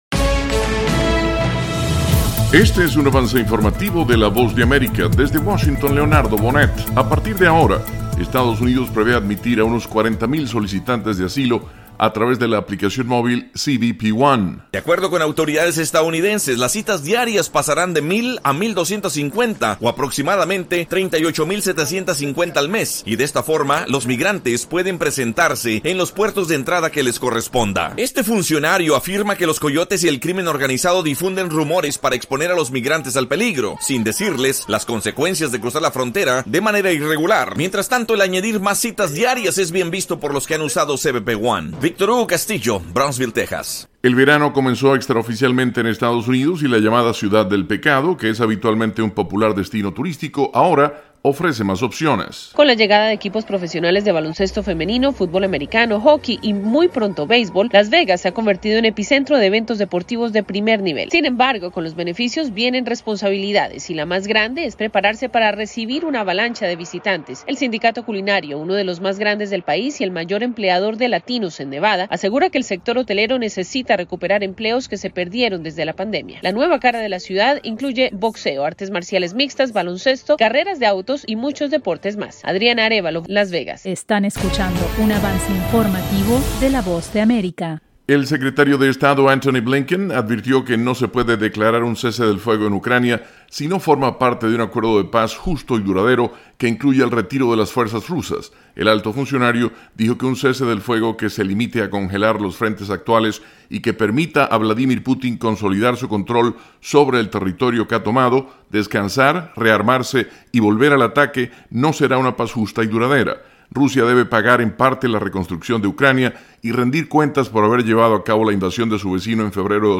Avance Informativo 1:00 PM
El siguiente es un avance informativo presentado por la Voz de América, desde Washington